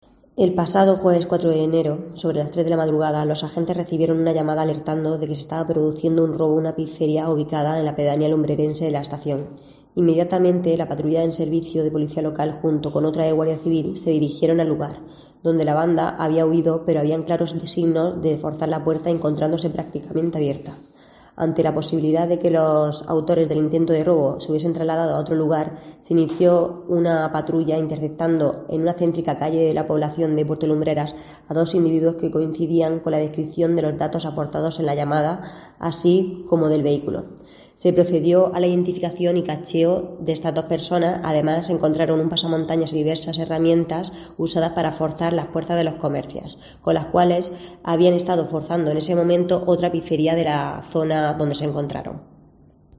Jerónima Reinaldos, concejala de VOX en Puerto Lumbreras